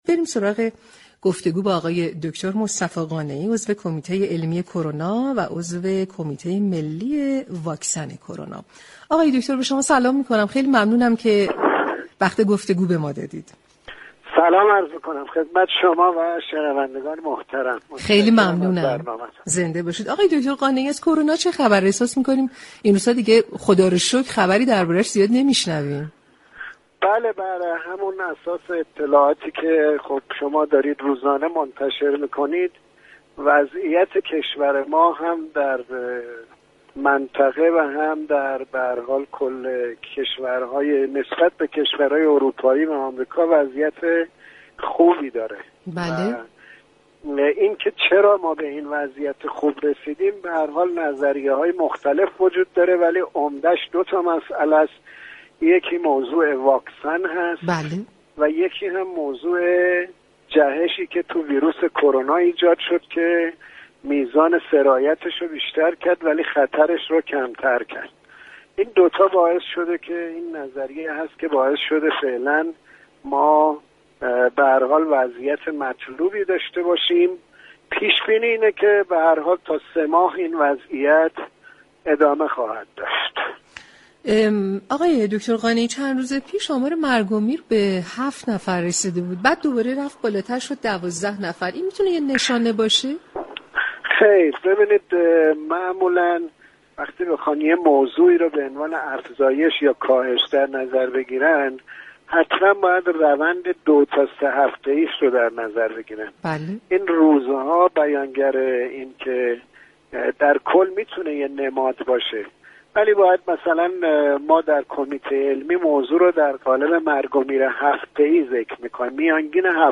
به گزارش پایگاه اطلاع رسانی رادیو تهران، مصطفی قانعی عضو كمیته علمی ستاد ملی مقابله با كرونا و عضو كمیته ملی واكسن كرونا در گفت و گو با برنامه تهران ما سلامت با اشاره به اینكه شرایط كرونا در ایران نسبت به دیگر كشورهای منطقه و كشورهای اروپایی و آمریكایی بهتر است اظهار داشت: كنترل كرونا در كشور دو دلیل عمده دارد.